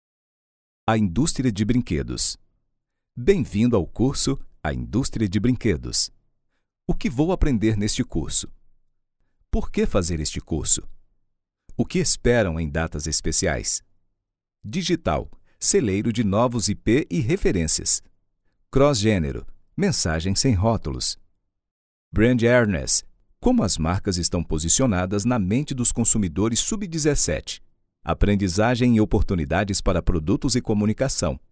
Male
Yng Adult (18-29), Adult (30-50)
E-Learning